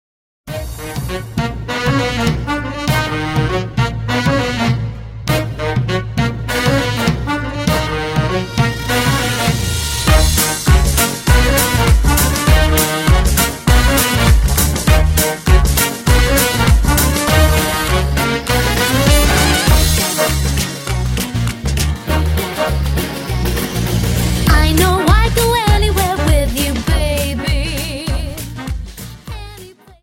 Dance: Quickstep 50